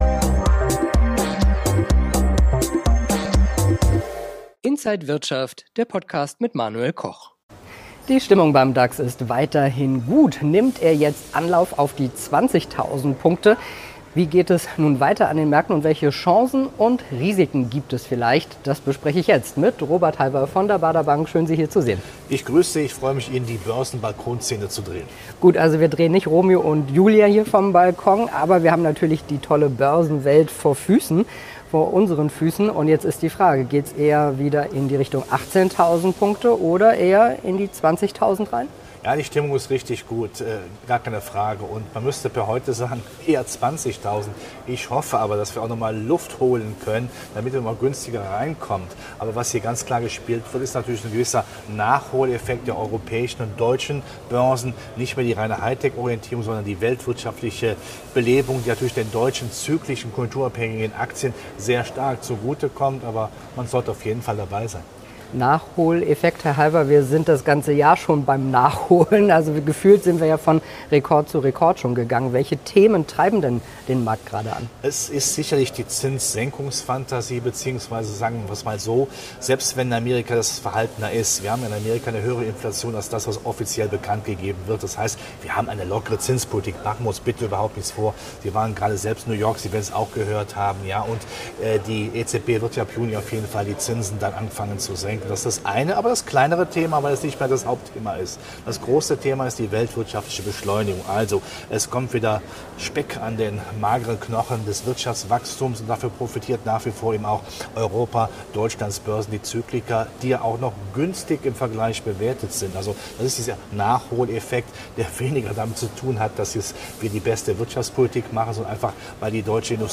Alle Details im Interview von Inside
an der Frankfurter Börse